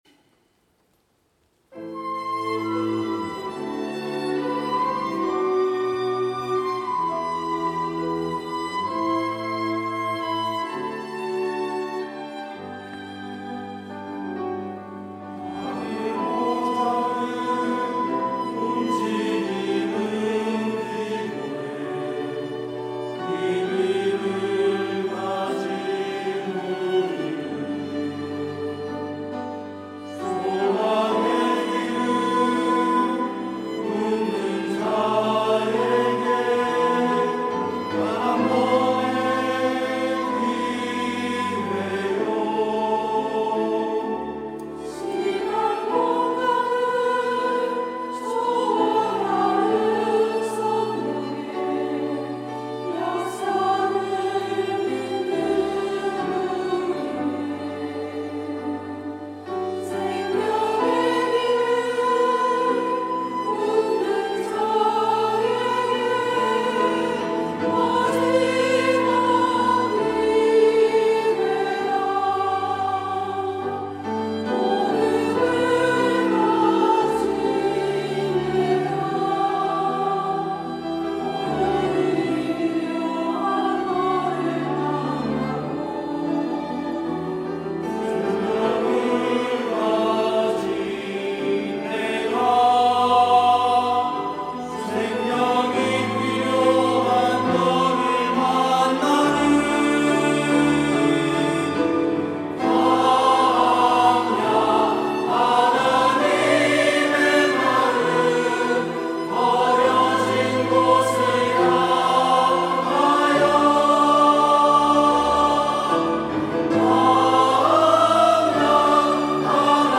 1부 찬양대